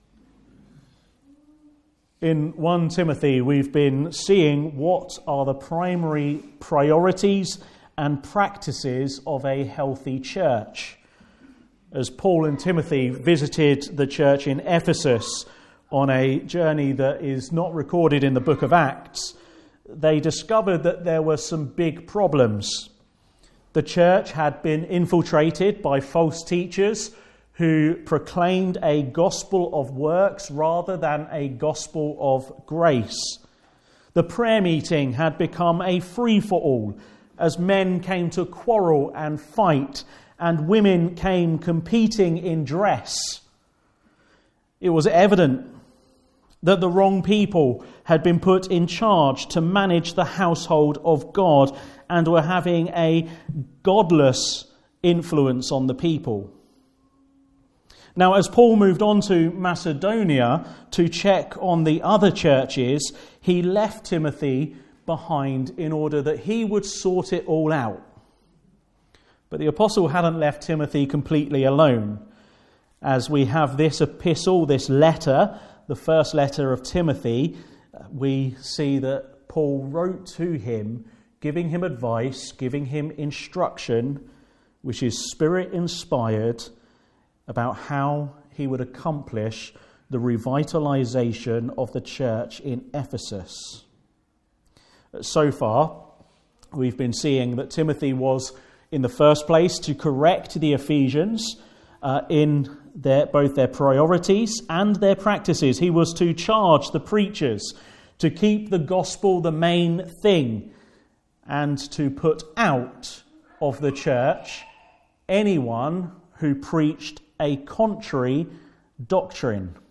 Service Type: Afternoon Service